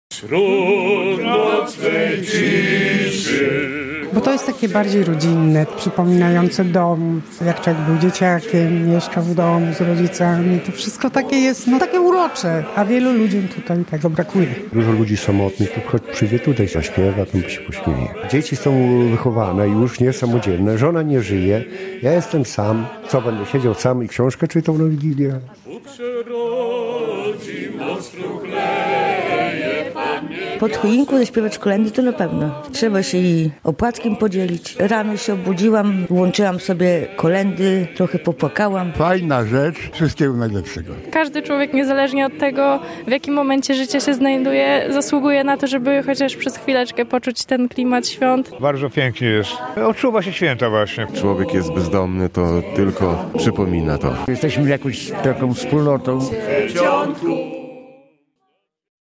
Śpiewali kolędy i wspominali święta z dzieciństwa. Bezdomni i samotni - podopieczni Prawosławnego Ośrodka Miłosierdzia Eleos, przy ozdobionej choince wspólnie przeżywali w wigilię (24.12) świąteczne chwile.